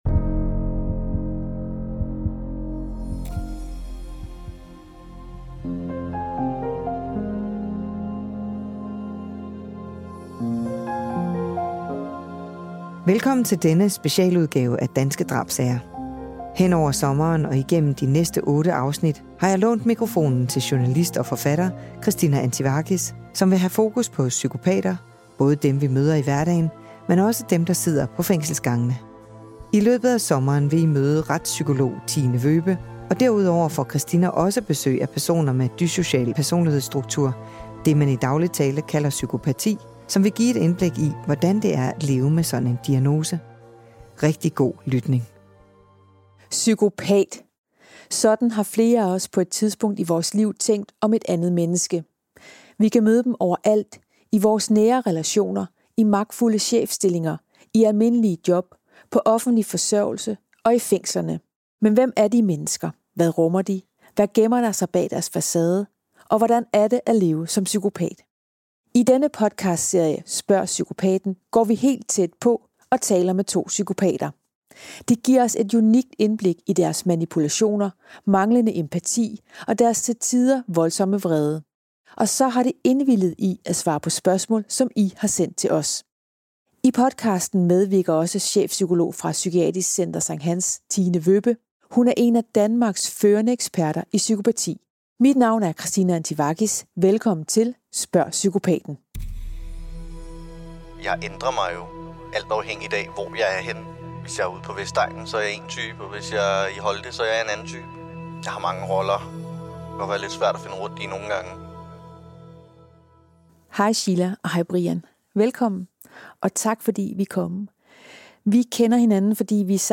Med i studiet har hun to psykopater, som giver et unikt indblik i deres dyssociale personlighedsstruktur og deres manipulerende tanker. Og så svarer de på lytternes spørgsmål om deres liv som psykopat.